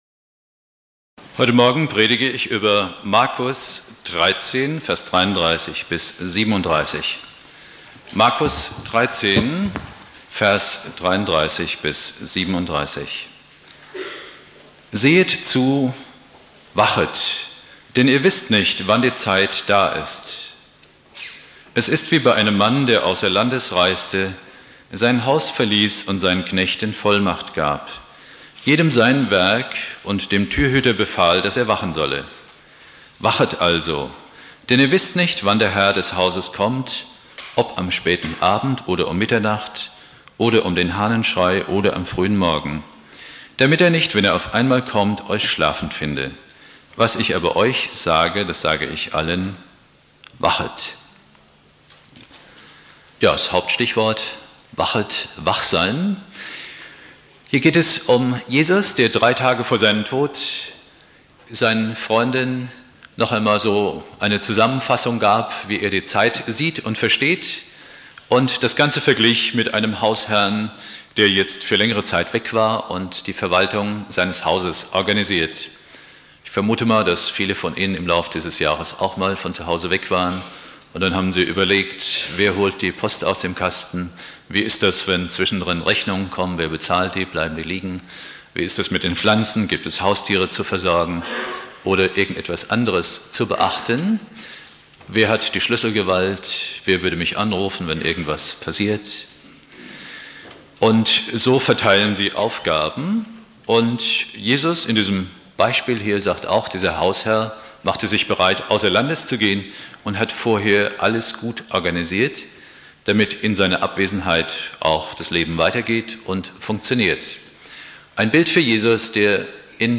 Predigt
Ewigkeitssonntag